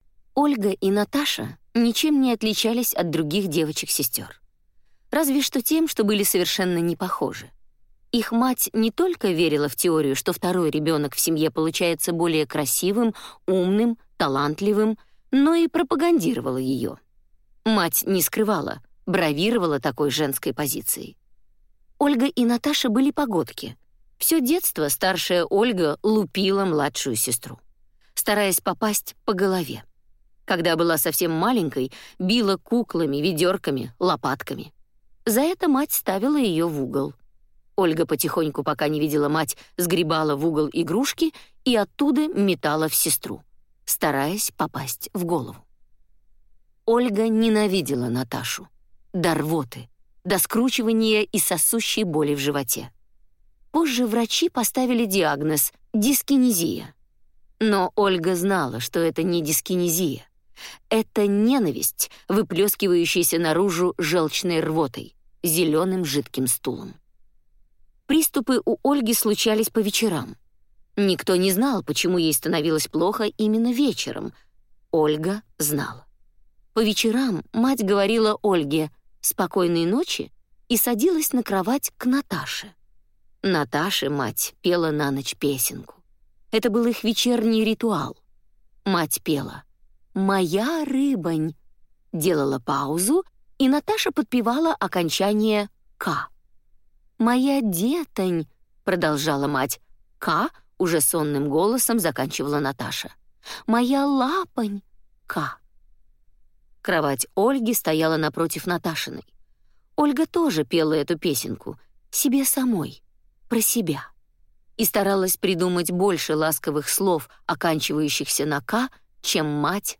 Аудиокнига Ласточ…ка | Библиотека аудиокниг